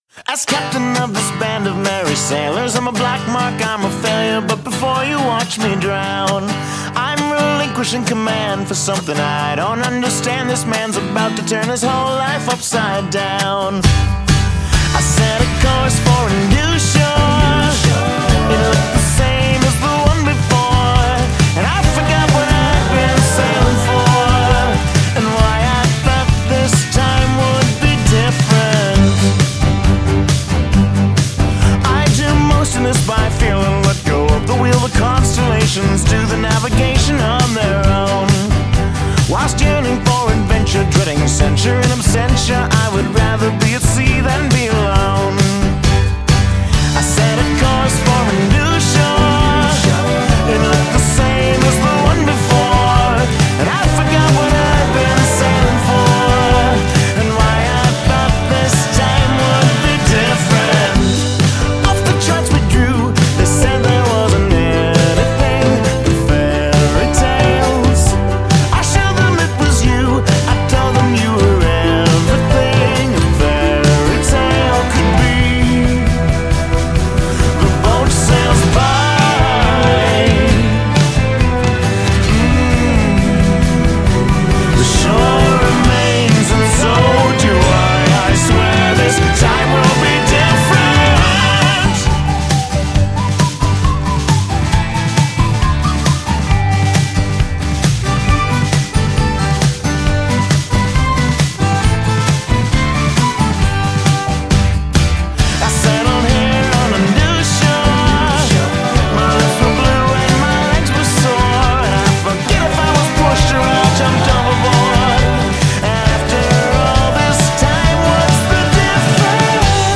with the whistling and accordion and everything!